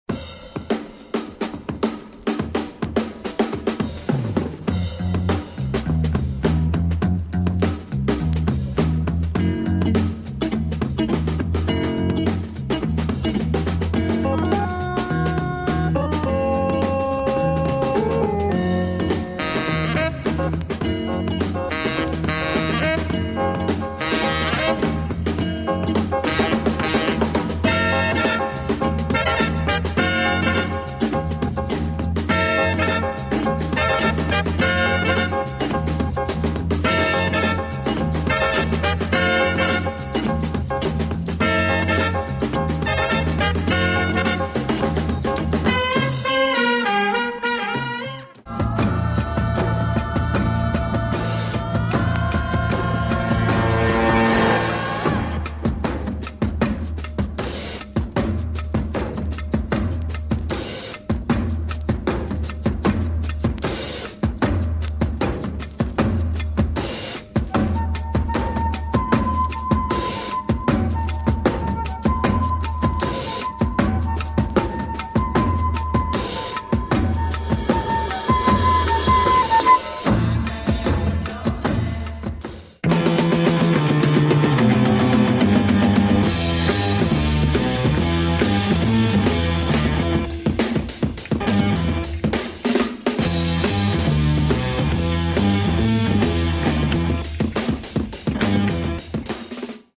Hungarian prog funk and soul, and a bible for drumbreaks !